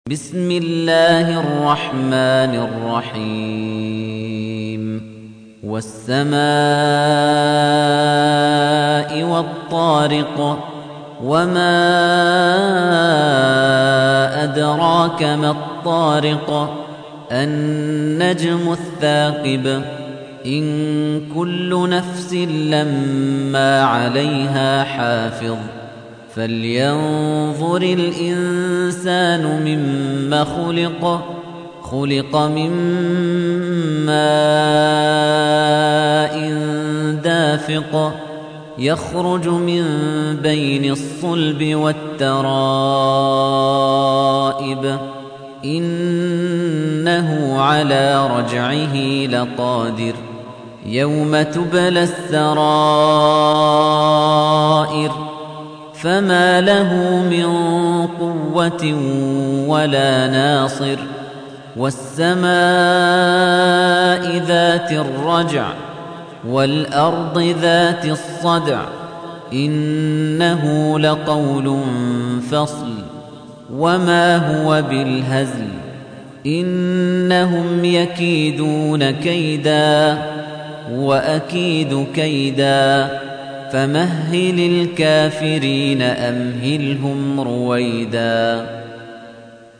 تحميل : 86. سورة الطارق / القارئ خليفة الطنيجي / القرآن الكريم / موقع يا حسين